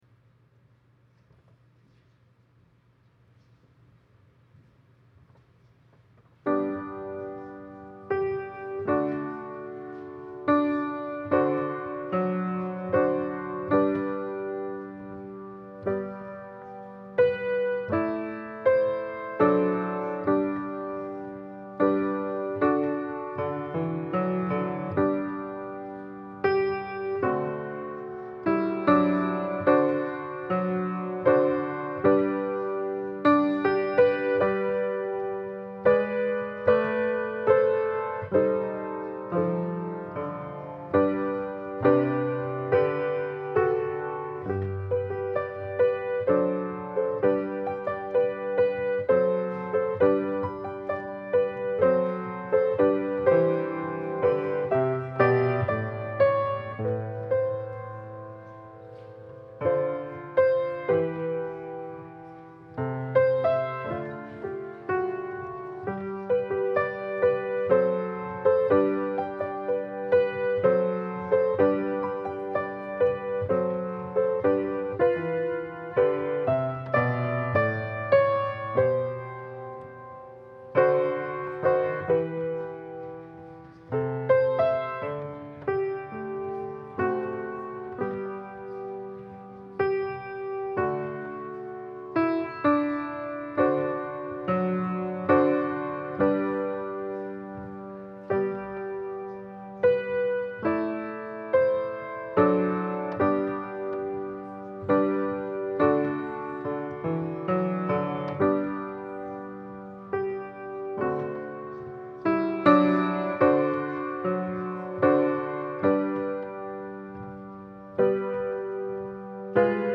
Passage: Luke 16: 1-13 Service Type: Sunday Service Scriptures and sermon from St. John’s Presbyterian Church on Sunday